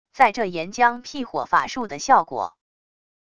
在这岩浆辟火法术的效果wav音频